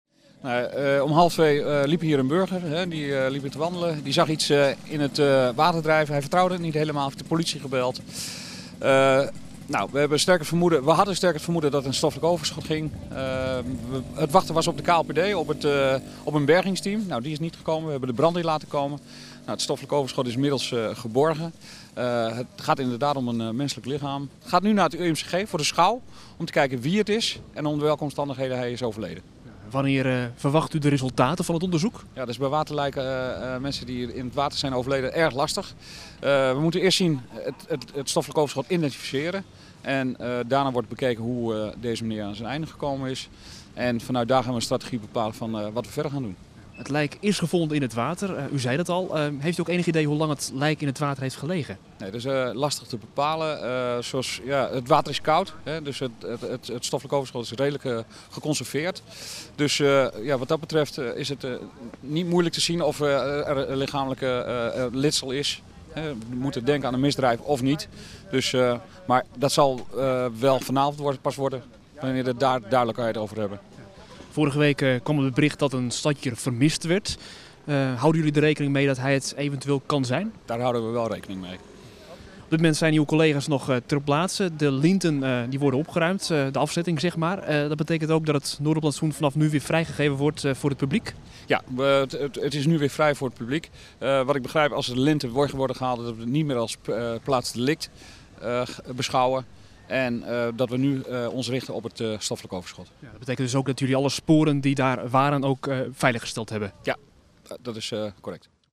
Woordvoerder